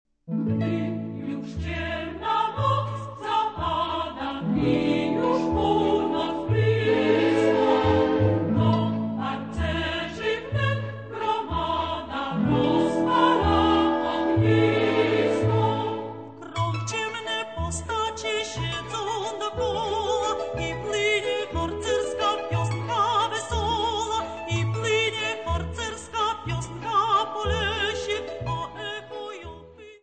24 Polish Scout songs.